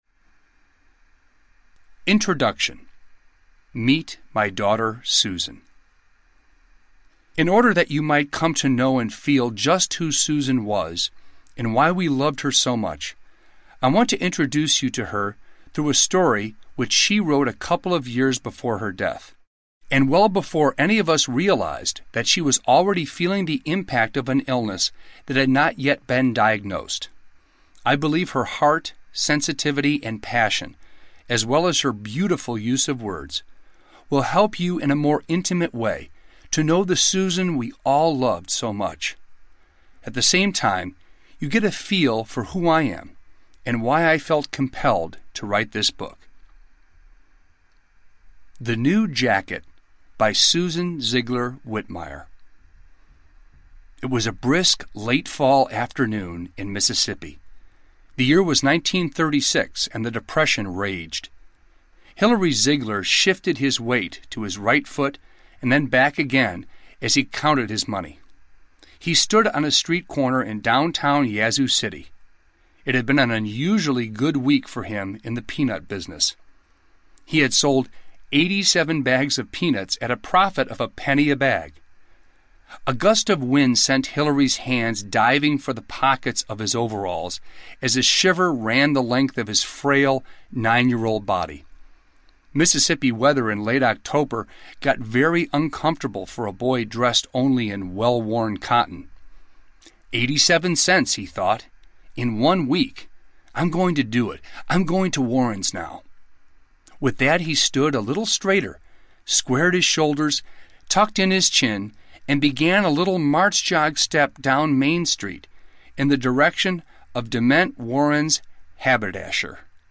Confessions of a Grieving Christian Audiobook
8.5 Hrs. – Unabridged